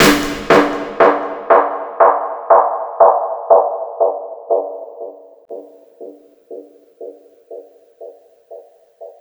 INSNAREFX2-R.wav